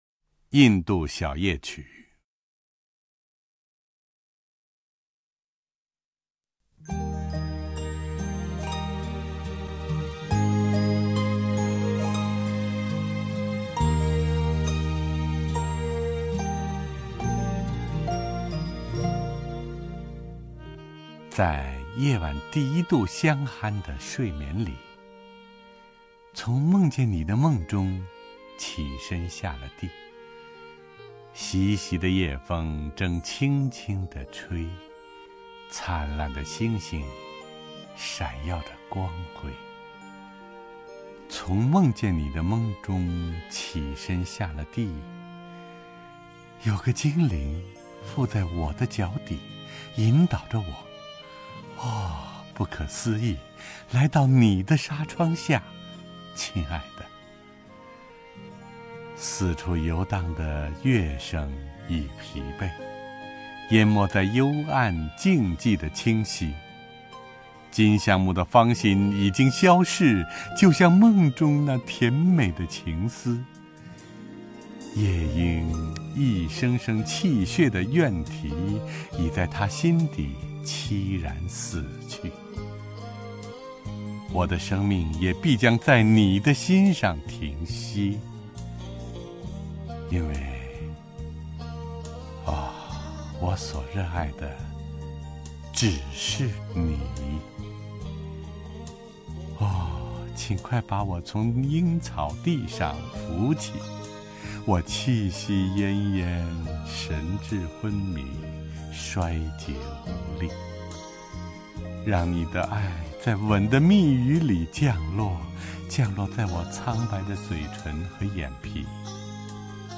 陈铎朗诵：《印度小夜曲》(（英）珀西·比希·雪莱)
名家朗诵欣赏 陈铎 目录